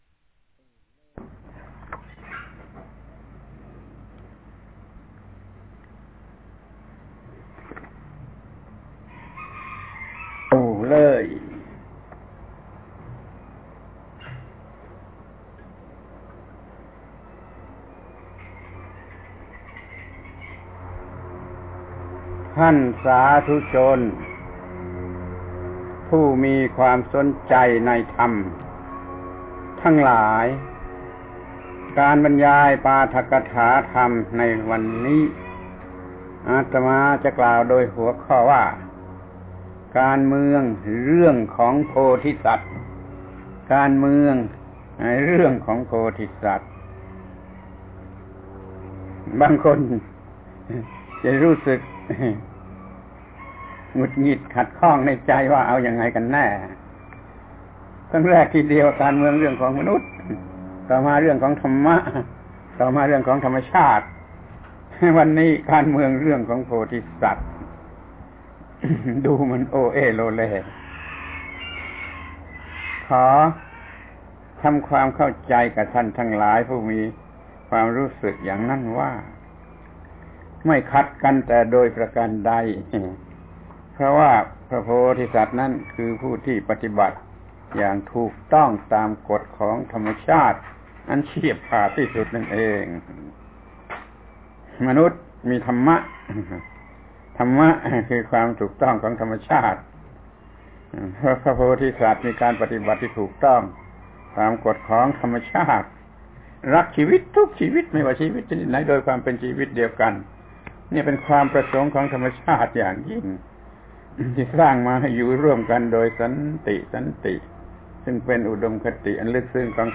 ปาฐกถาธรรมทางวิทยุ ชุด การเมือง การเมืองเรื่องของโพธิสัตว์